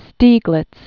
(stēglĭts), Alfred 1864-1946.